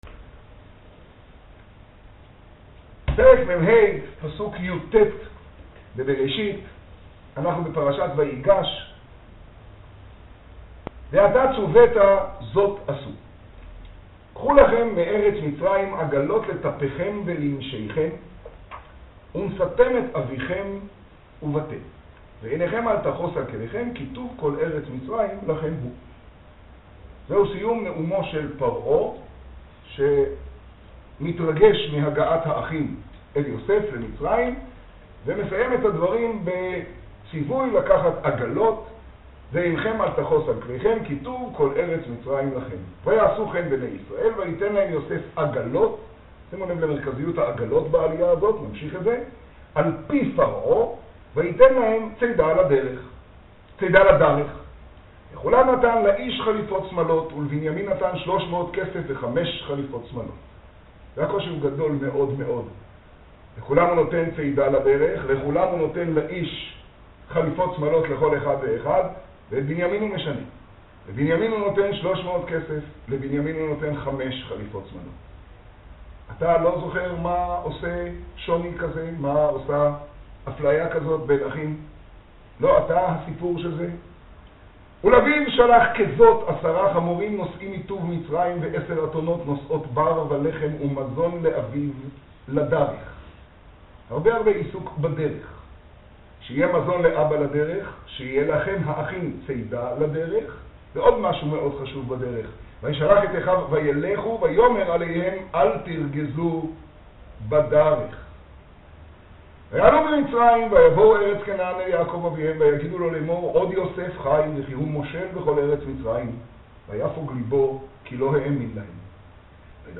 האזנה קטגוריה: מגדל - היום בפרשה , שיעור , תוכן תג: בראשית , ויגש , חומש , תשעה → רמבם יומי – ב טבת תשעה רמבם יומי – ג טבת תשעה ←